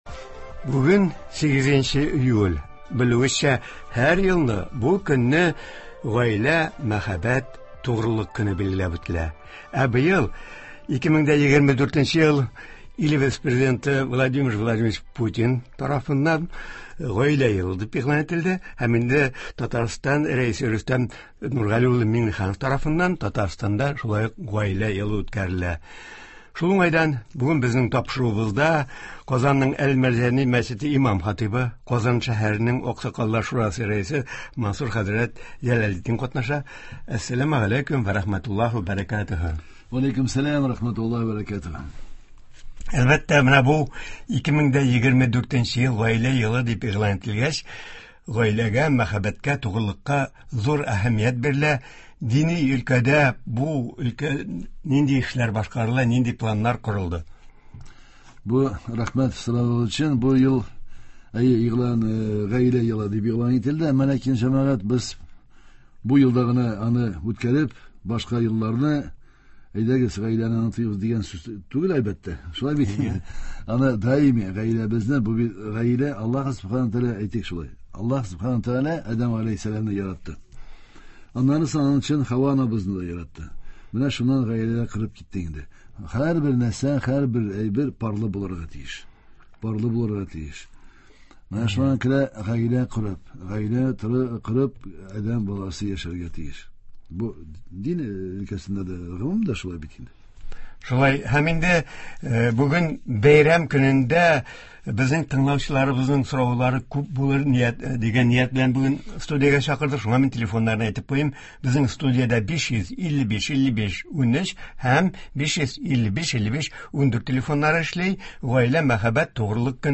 Туры эфир (08.07.24)